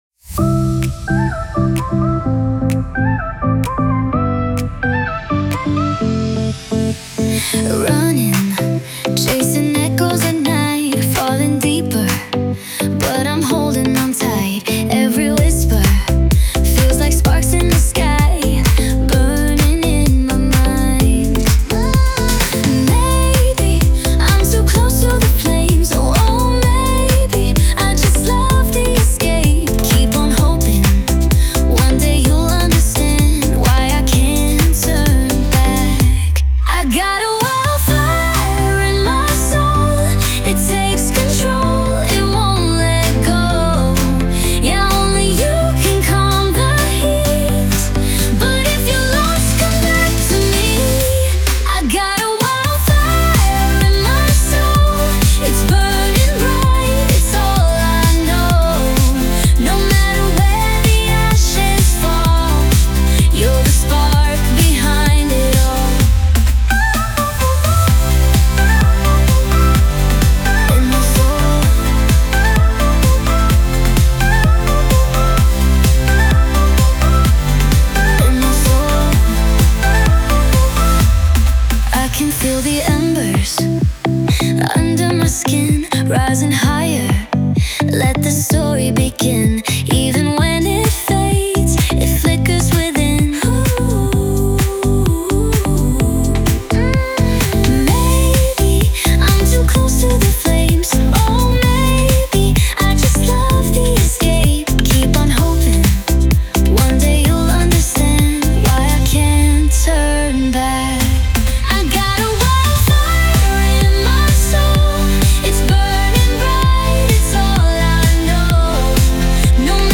Tropical House | Melodic Dance | Feel-Good Electronic